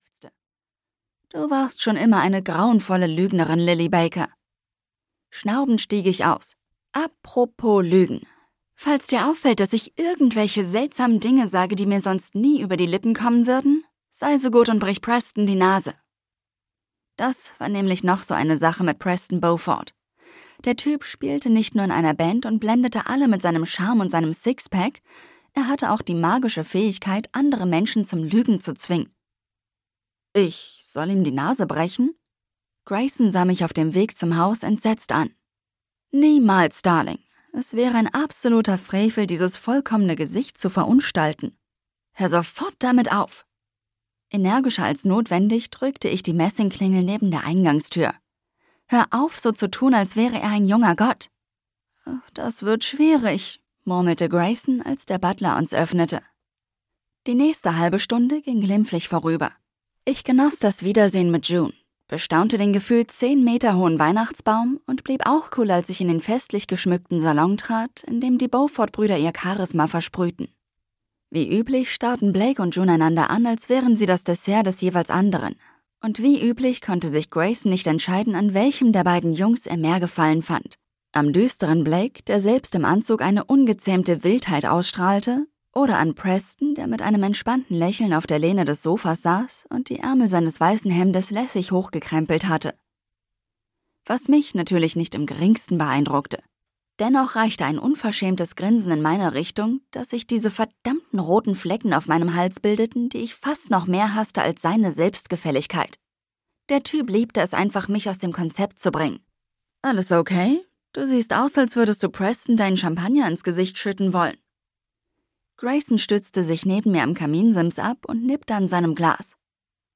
December Dreams - Ein magisches Feuerwerk der Gefühle - Rose Snow - Hörbuch